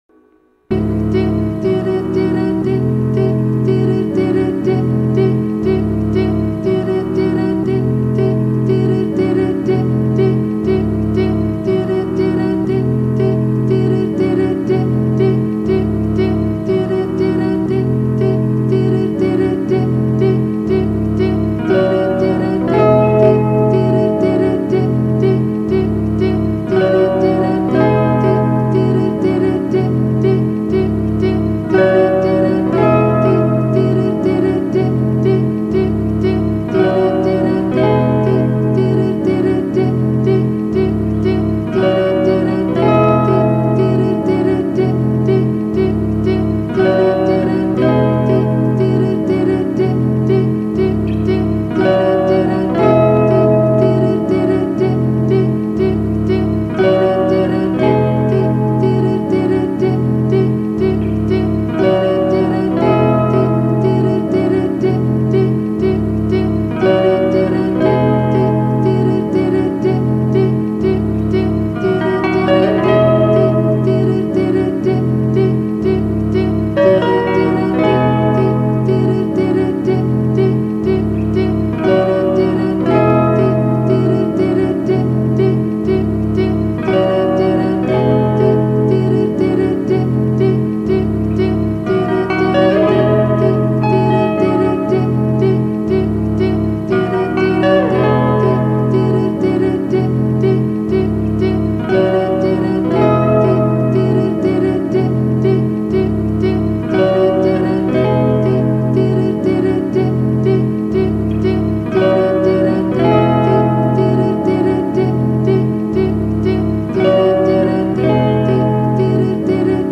lofi and or ethereal tracks i like :3